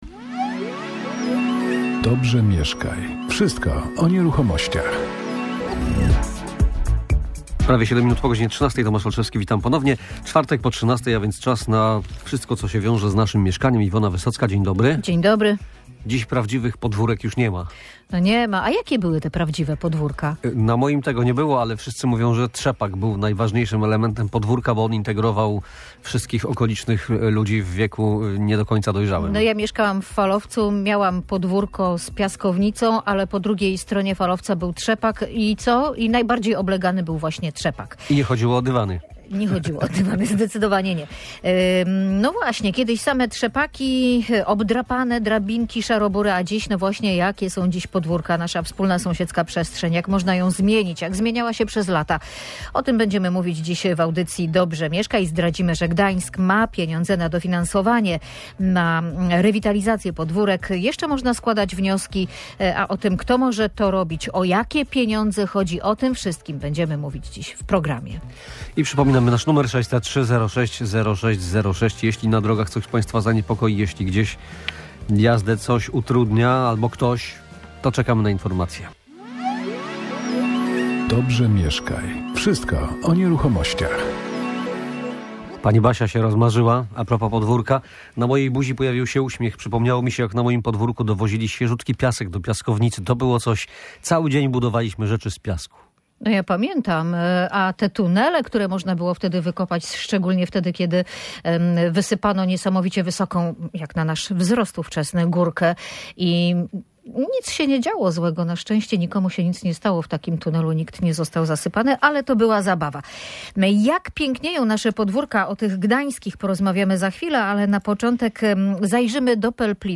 Jakie dziś są nasze podwórka, wspólna sąsiedzka przestrzeń? Jak zmieniała się przez lata i jak można ją poprawić? O tym rozmawiali goście audycji Dobrze Mieszkaj.